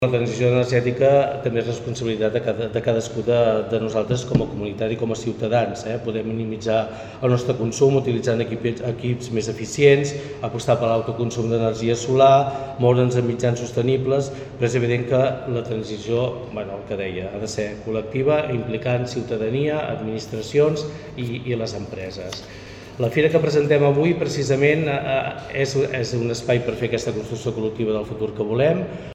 El president del Consell Comarcal del Baix Empordà, Enric Marquès, afegeix que la transició energètica, a banda d’administracions i empreses, també és responsabilitat de la ciutadania, amb l’objectiu de minimitzar el seu consum.